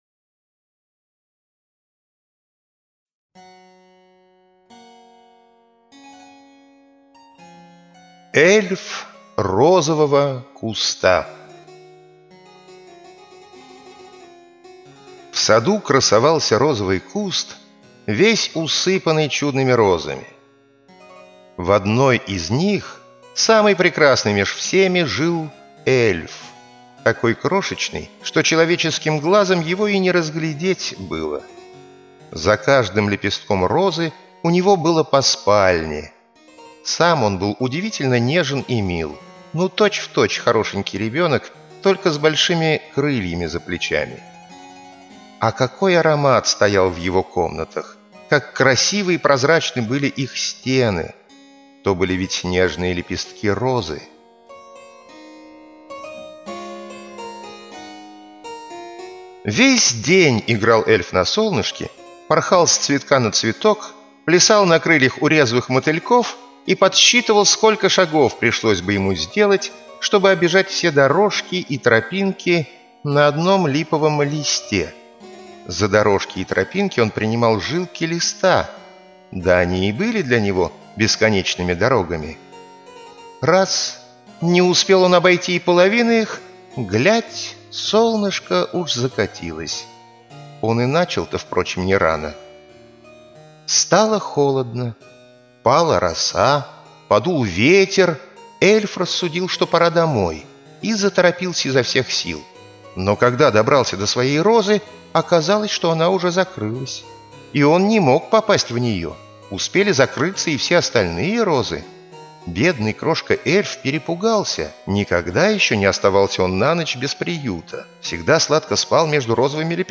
Аудиосказка Эльф розового куста - слушать сказку Андерсена онлайн бесплатно